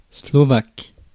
Ääntäminen
Ääntäminen France: IPA: [slɔ.vak] Haettu sana löytyi näillä lähdekielillä: ranska Käännös Substantiivit 1. eslovaco {m} Adjektiivit 2. eslovaco {m} Suku: f .